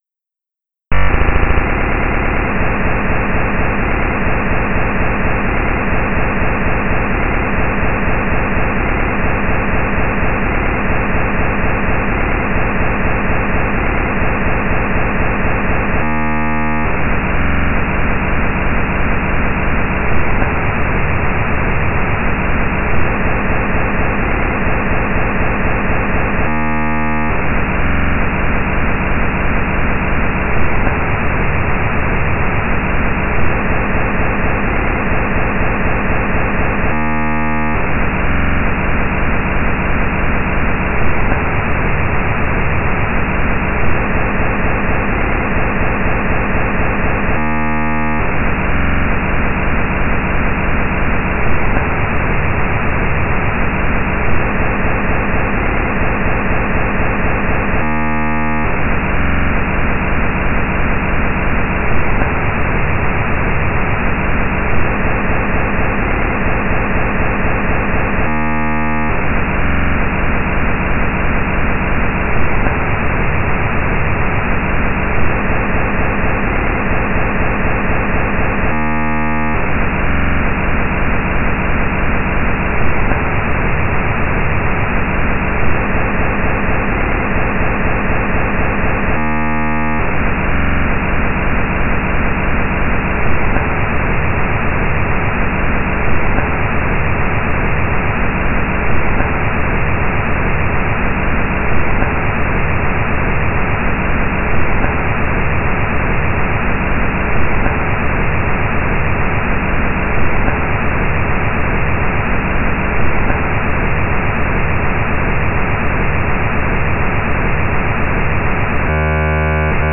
weird, electronica, surreal, experimental,